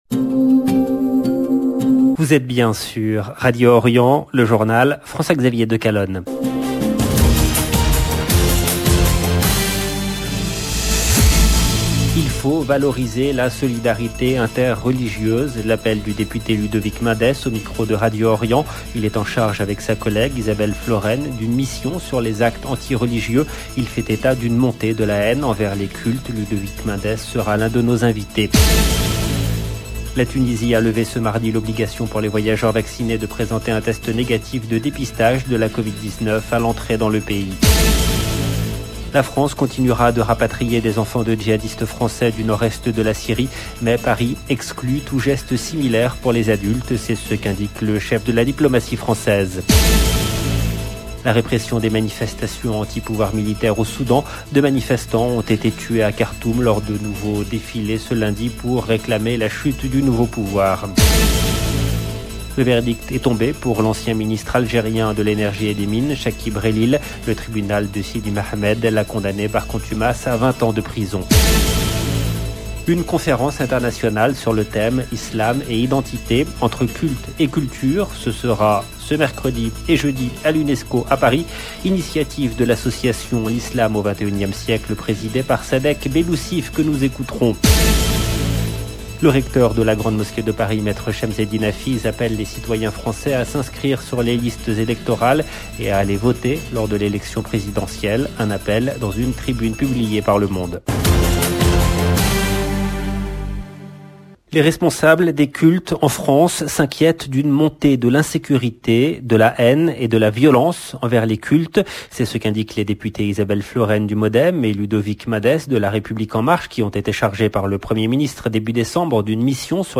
LB JOURNAL EN LANGUE FRANÇAISE
Ludovic Mendes sera l’un de nos invités. La Tunisie a levé ce mardi l’obligation pour les voyageurs vaccinés de présenter un test négatif de dépistage de la Covid-19 à l’entrée dans le pays.